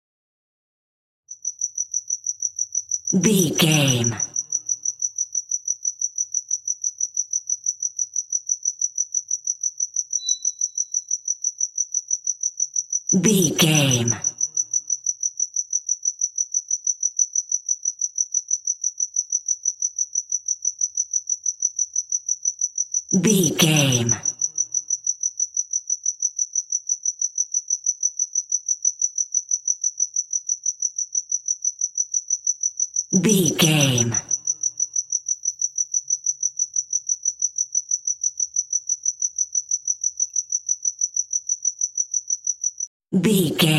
City cricket 4
Sound Effects
urban
ambience